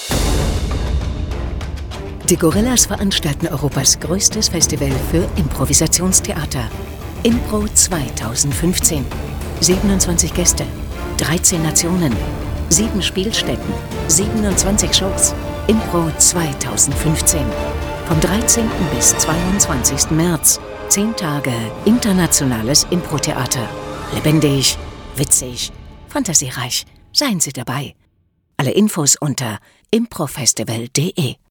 Lektor